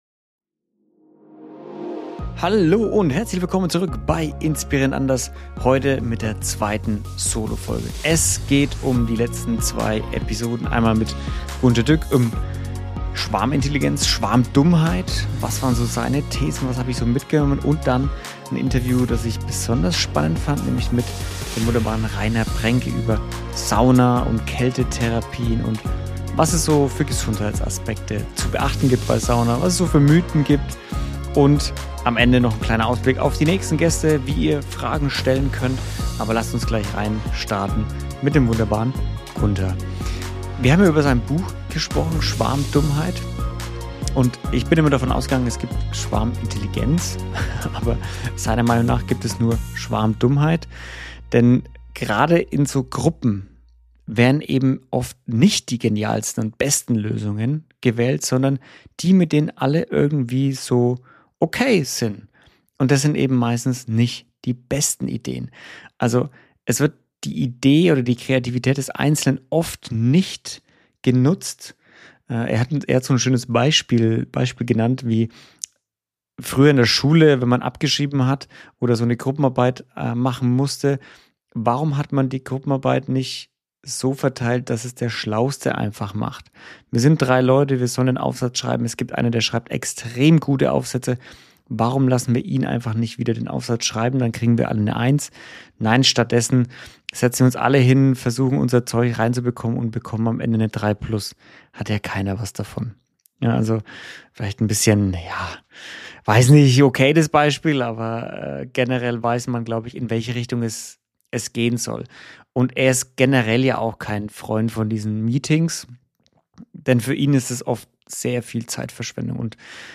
In dieser Solo-Folge nehme ich dich mit hinter die Kulissen von zwei Gesprächen, die unterschiedlicher kaum sein könnten – und doch einen gemeinsamen Kern haben: Verantwortung.